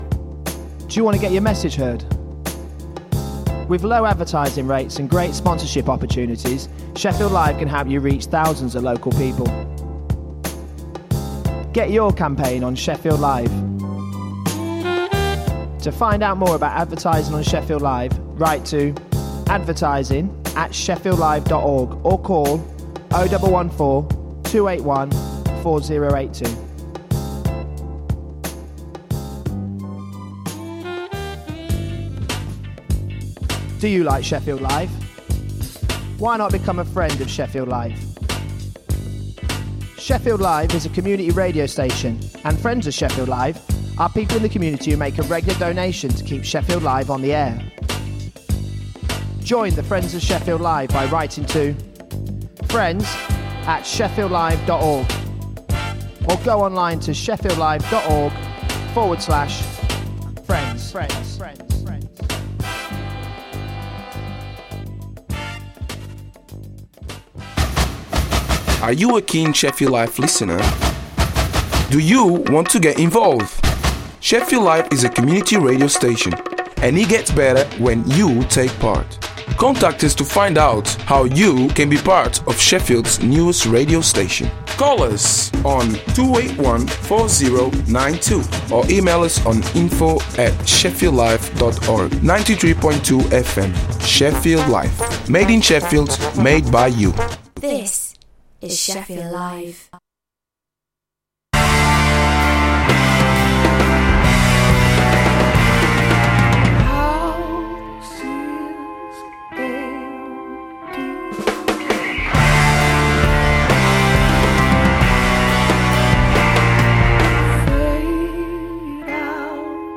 A programme that introduces all different styles of Latin music from its roots and at the same time inform the audience of the latest issues in Latin America, also interviews with artists visiting the city (not necessarily South American).